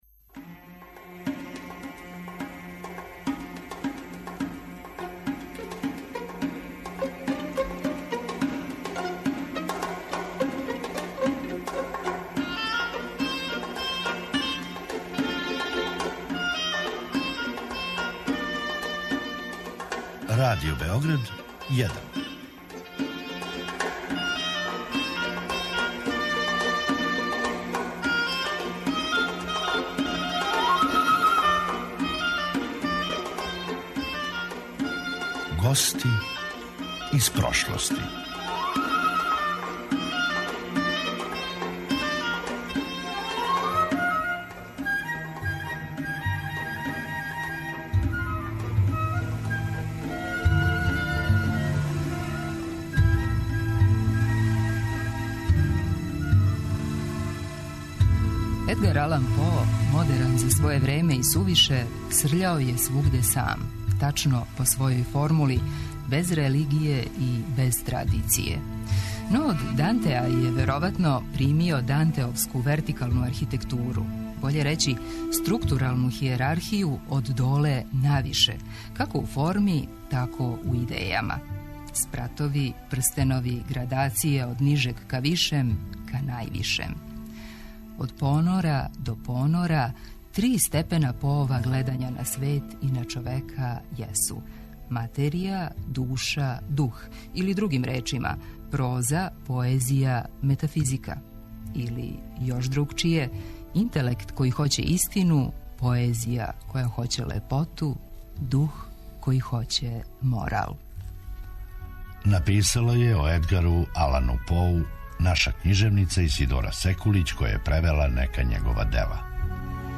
драмски уметник